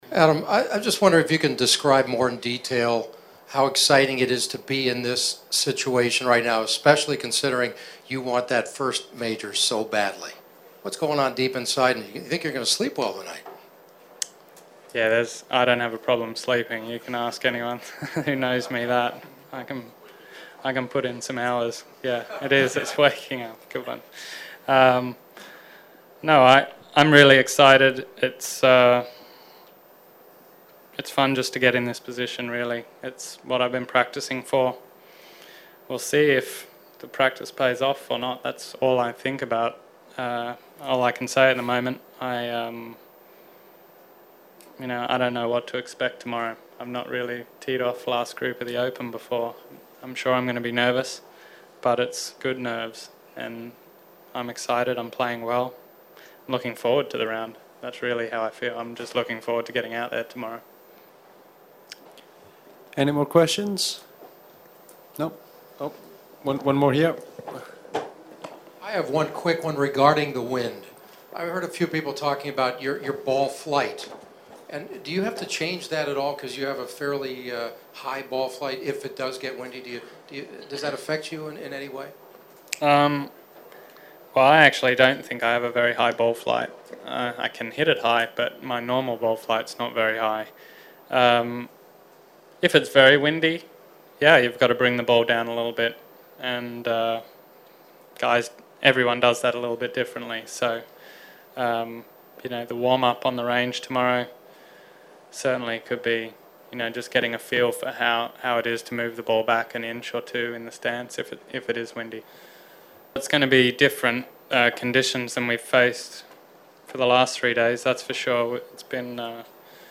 I’ve chatted with Adam a few times since but nothing compares to last year at The Open Championship where he was first the 3rd round leader at Royal Lytham and St. Anne’s. The following is our post match interaction on the chances of winning his first major at the time.